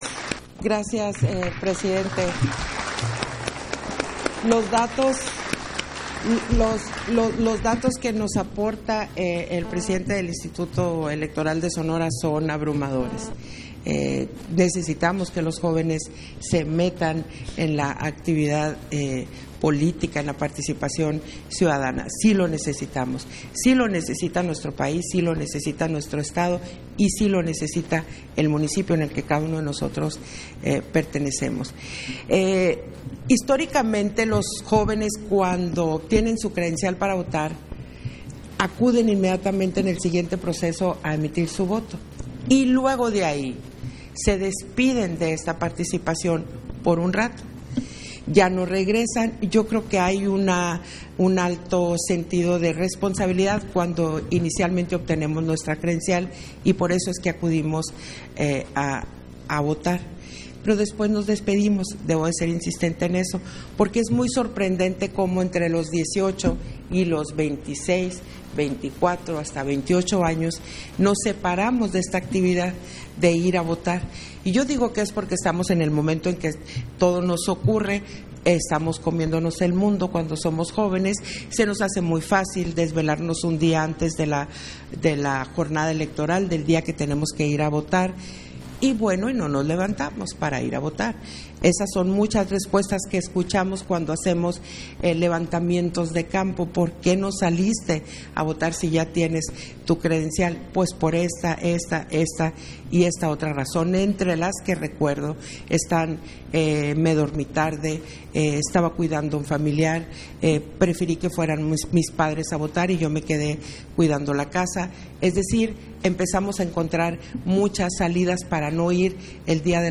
Intervenciones de Guadalupe Taddei, en el Foro Juventud y Democracia